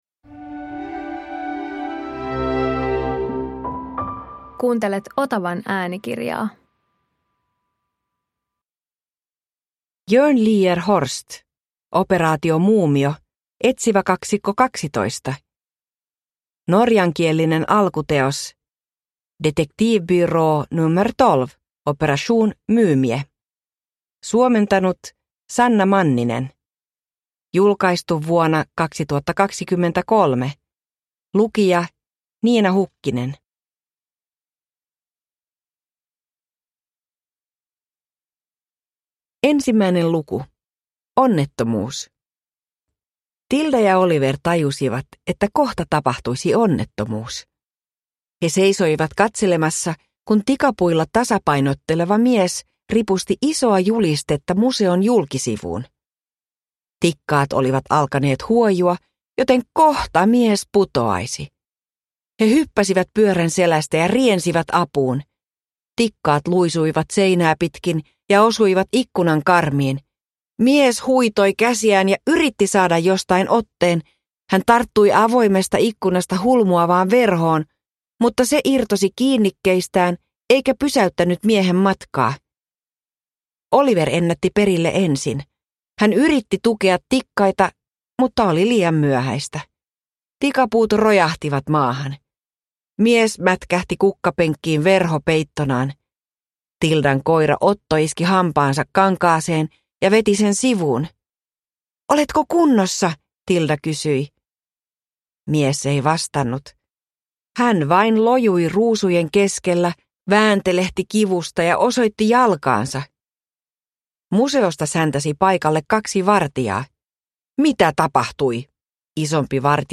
Operaatio Muumio – Ljudbok – Laddas ner